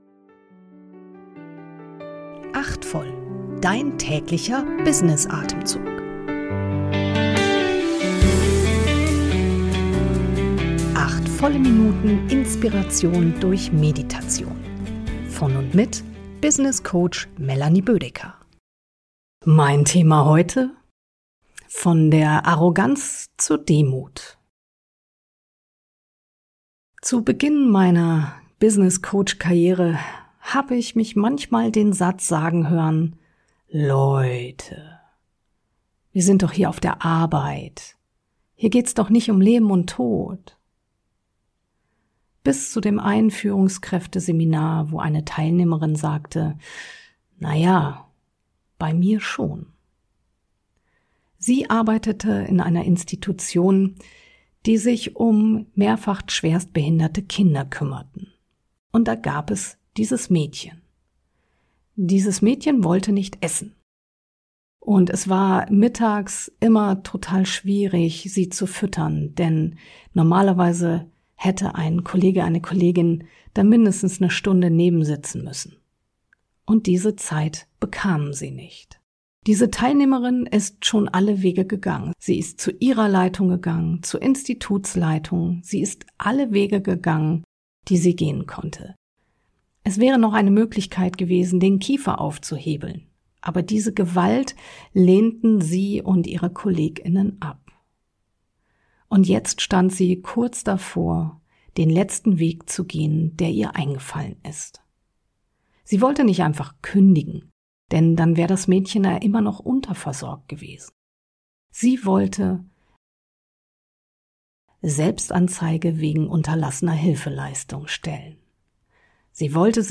Kurz-Meditation.